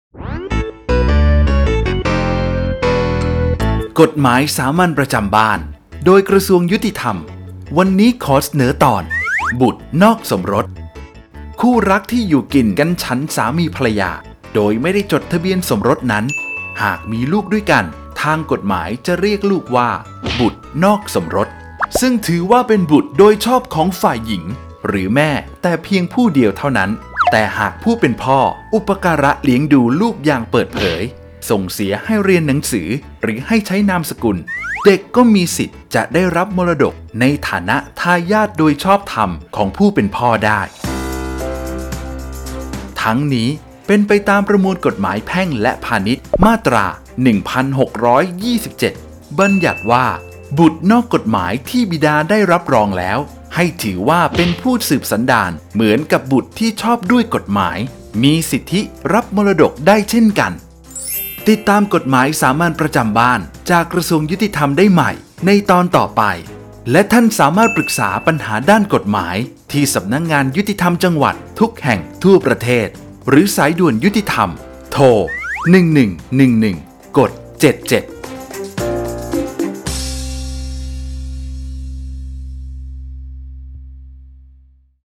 กฎหมายสามัญประจำบ้าน ฉบับภาษาท้องถิ่น ภาคกลาง ตอนบุตรนอกสมรส
ลักษณะของสื่อ :   คลิปเสียง, บรรยาย